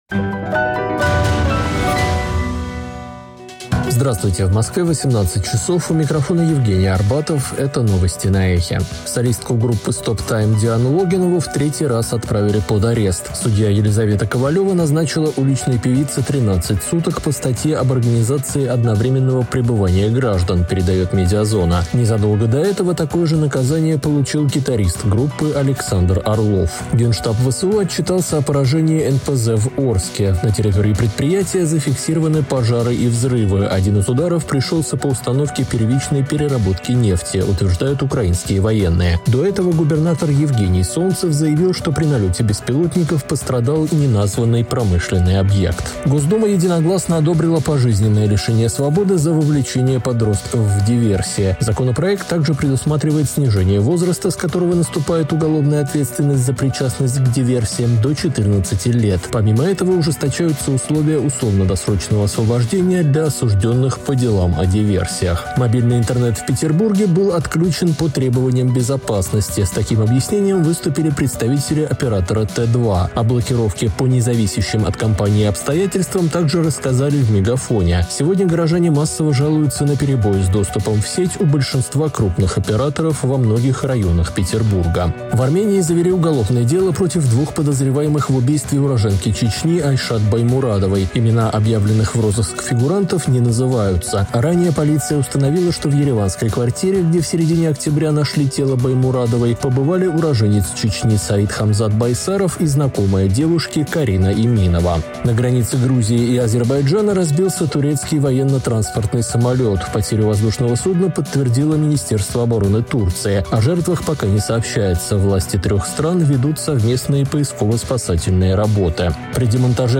Новости